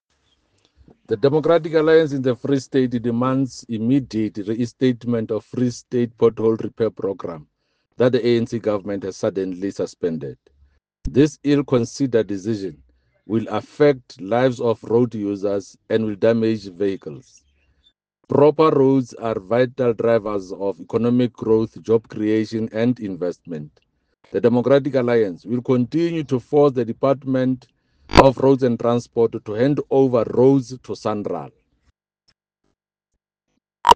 Sesotho soundbites by Jafta Mokoena MPL and